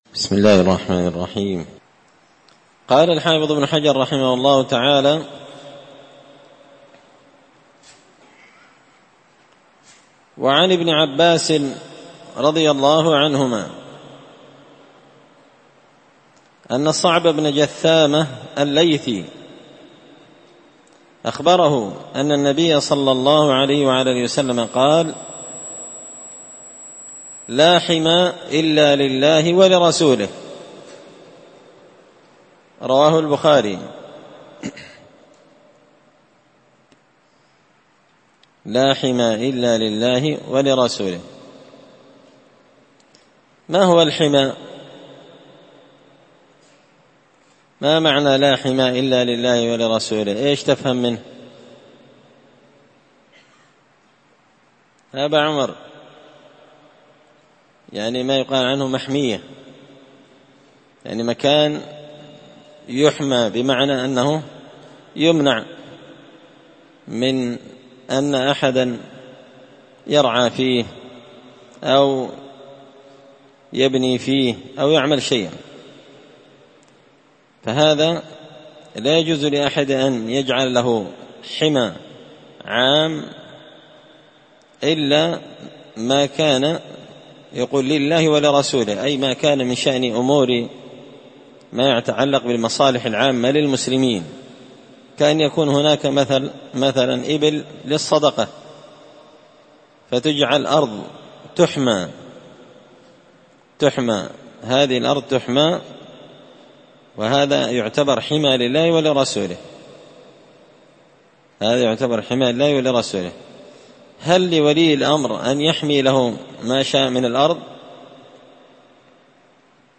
مسجد الفرقان_قشن_المهرة_اليمن